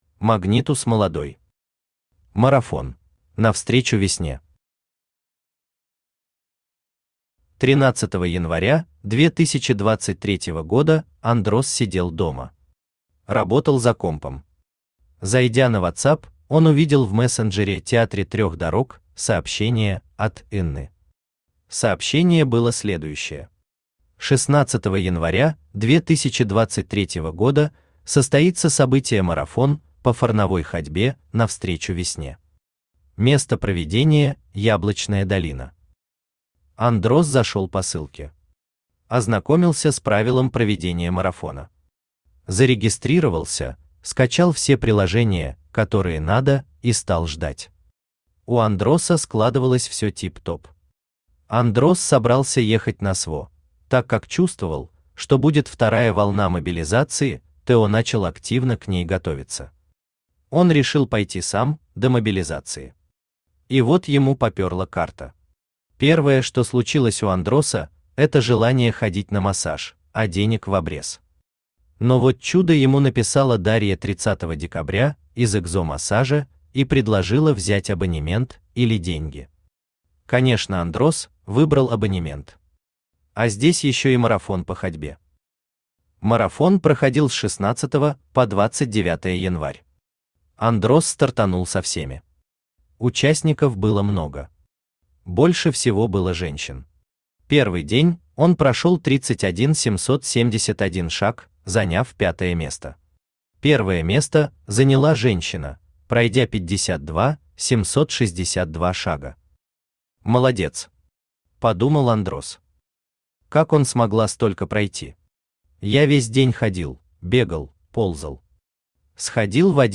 Aудиокнига Марафон Автор Магнитус Молодой Читает аудиокнигу Авточтец ЛитРес.